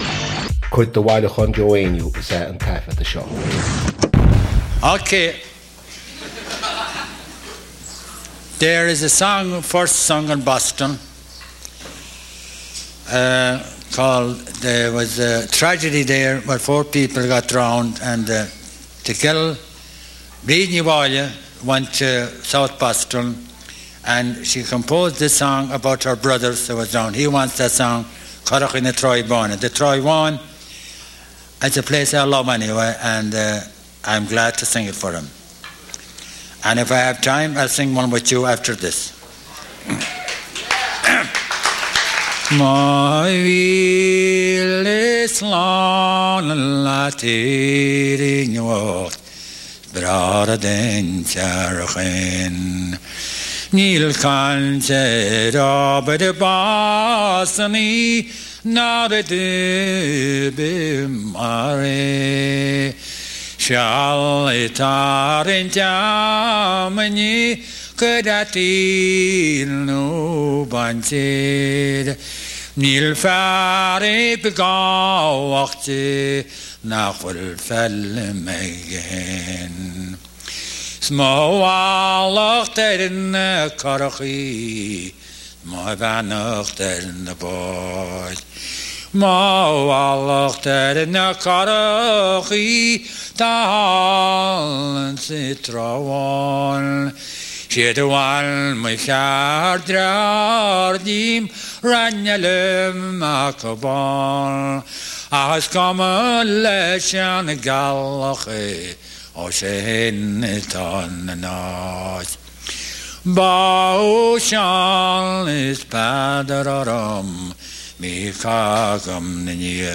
• Catagóir (Category): song.
• Ainm an té a thug (Name of Informant): Joe Heaney.
• Suíomh an taifeadta (Recording Location): San Francisco, California, United States of America.
• Ocáid an taifeadta (Recording Occasion): concert.